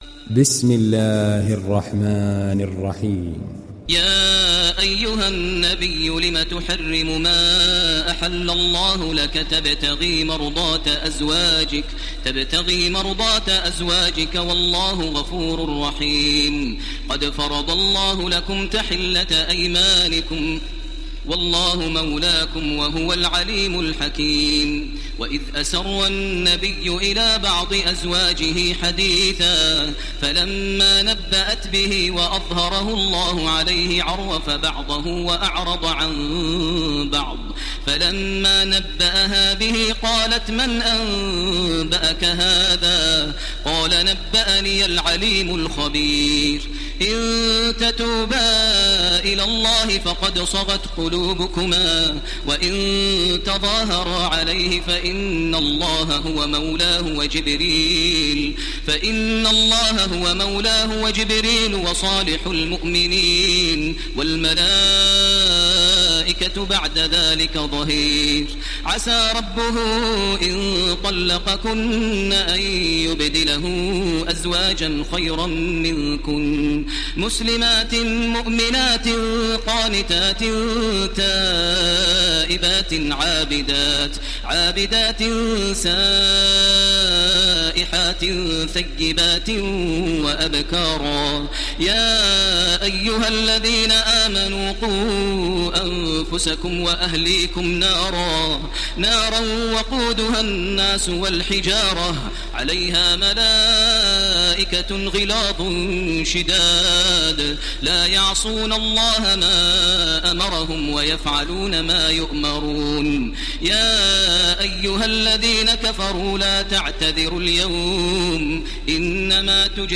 Download Surat At Tahrim Taraweeh Makkah 1430